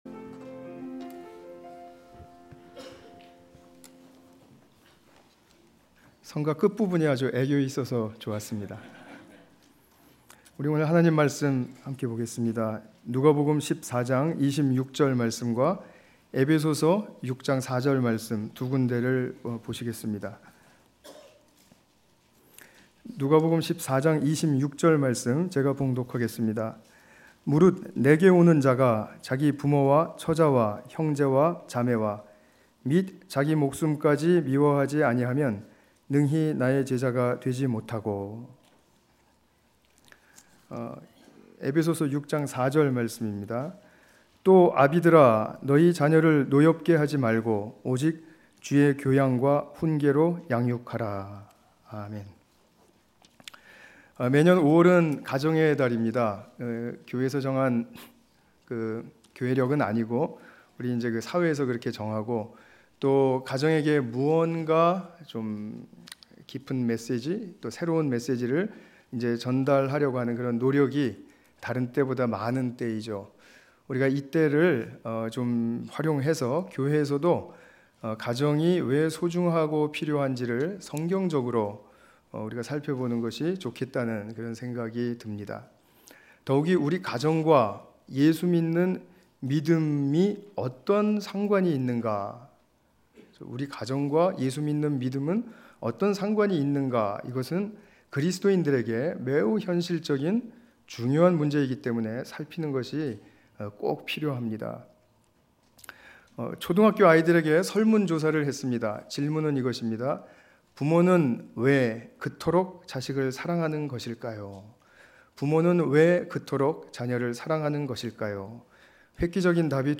에베소서 6장 4절 관련 Tagged with 주일예배